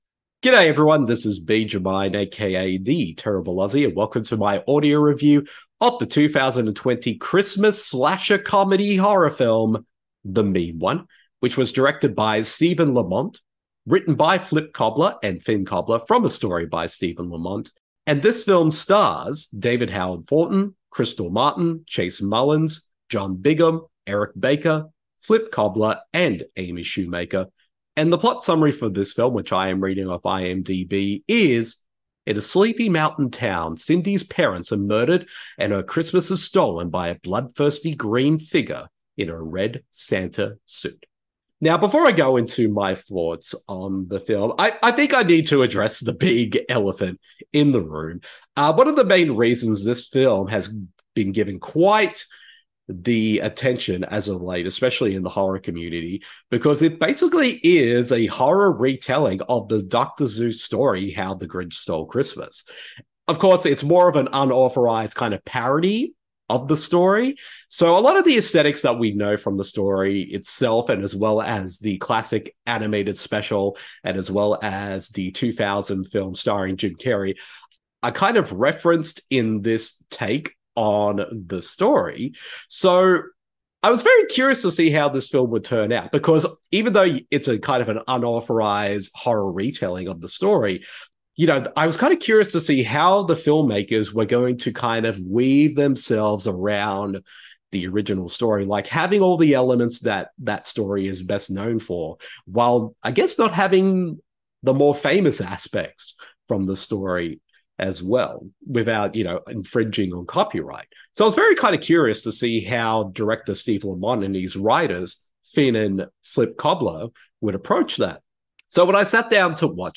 [Audio Review] The Mean One (2022)